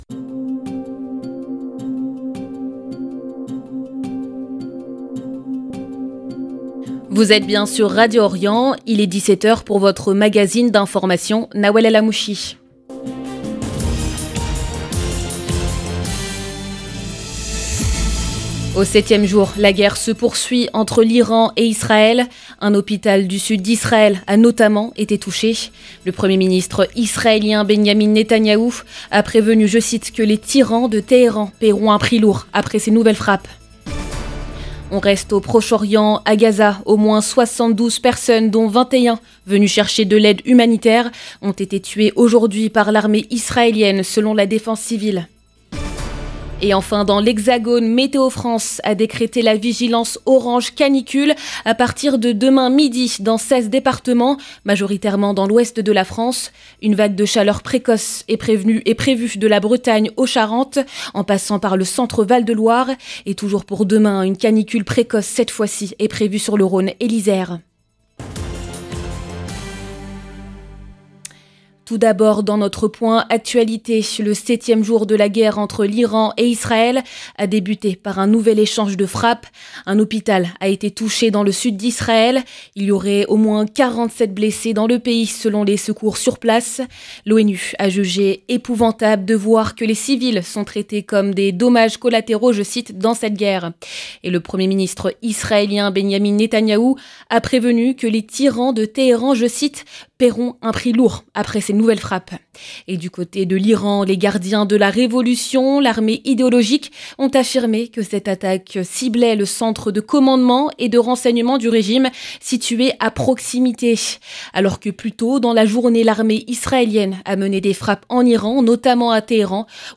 Magazine de l'information de 17H00 du 19 juin 2025